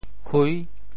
匮（匱） 部首拼音 部首 匚 总笔划 11 部外笔划 9 普通话 kuì guì 潮州发音 潮州 kui6 文 潮阳 kui6 澄海 kui6 揭阳 kui6 饶平 kui6 汕头 kui6 中文解释 匮 <名> kui (形声。